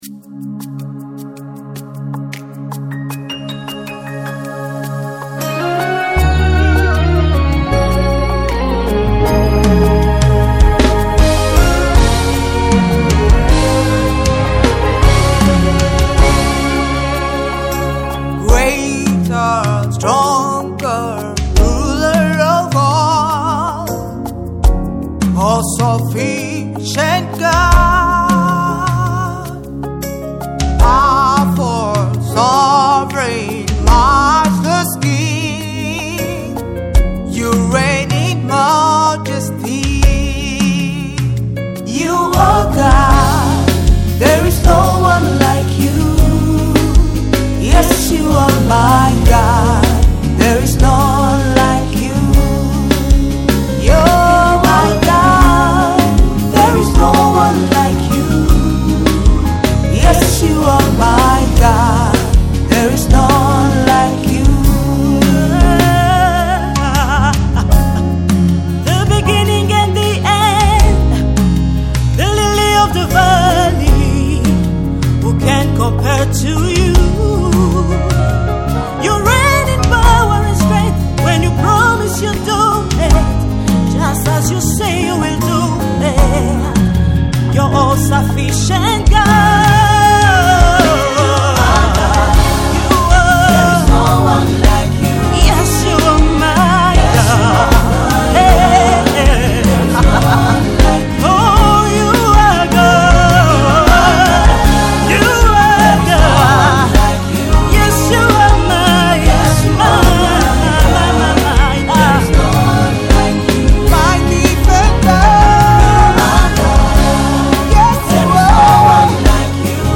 The Nigeria-based gospel minister